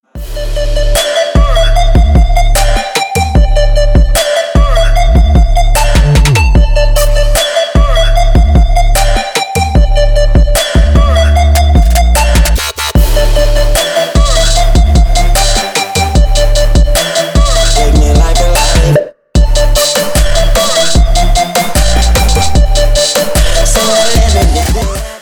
Trap рингтоны
Трэп нарезка на мобильник